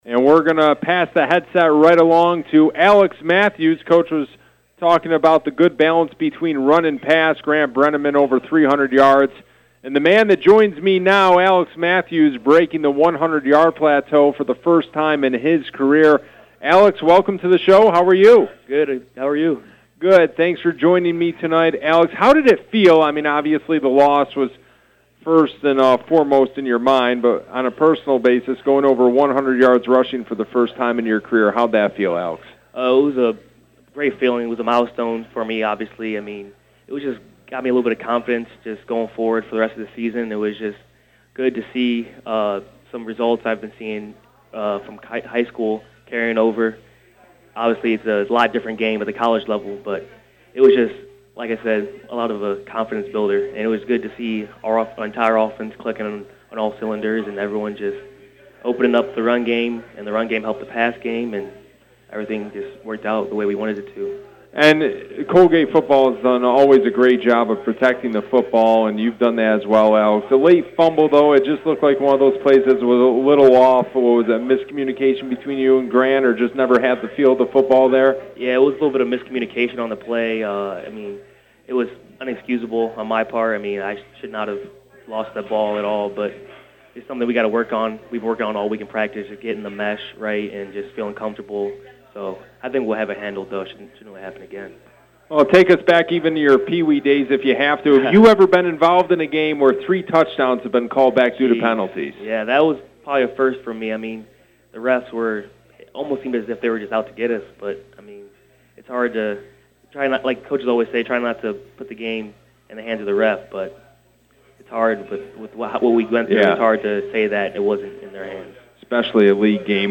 Coaches Show interview